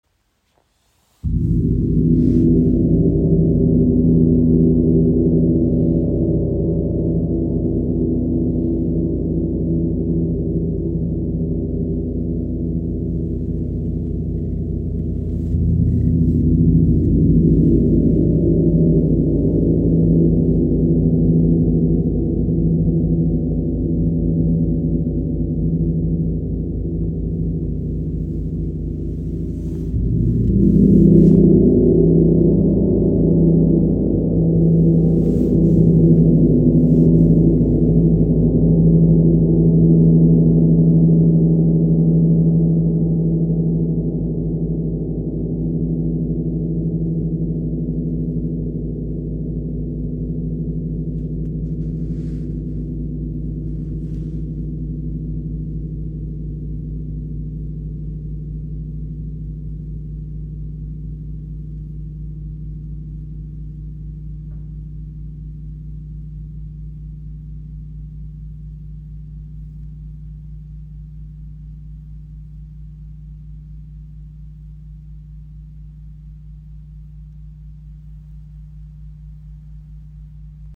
Klangbeispiel
Sein Klang ist tief, ausgewogen und reich an Obertönen, die eine Atmosphäre zwischen Erdentiefe und kosmischer Weite erschaffen. Mit Reibungsschlägeln wie den B Love Flumies entstehen sanfte, traumhafte Wal- und Delfinsounds.
WOM KI Gong – Der Klang der inneren Weite | ø 120 cm | Edelstahl-Gong Wie aus der Tiefe des Meeres geboren, singt dieser Gong in Tönen von Walgesang und innerer Weite.